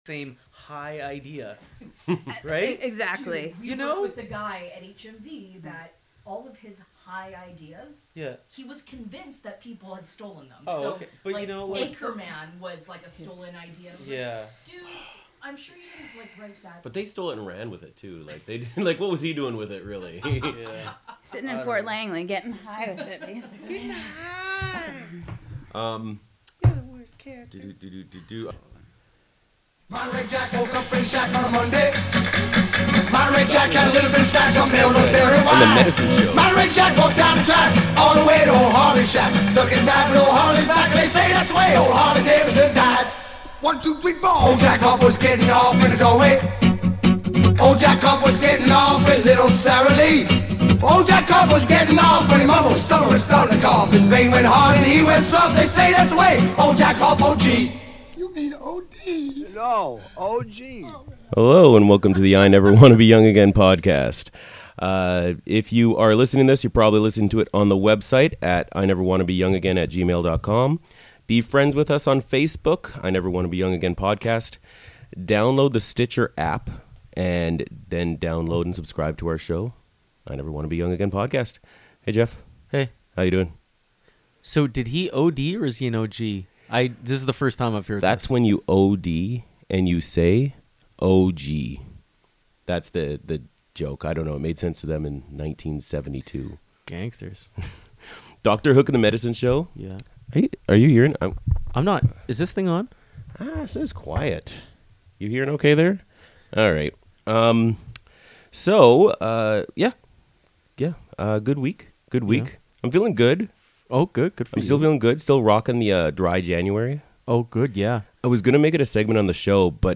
This week in the studio we have comedian